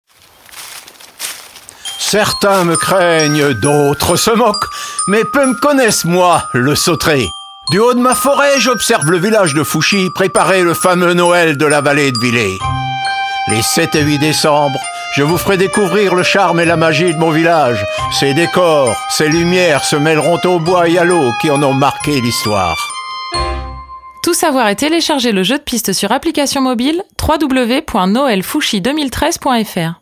Radio
Spot publicitaire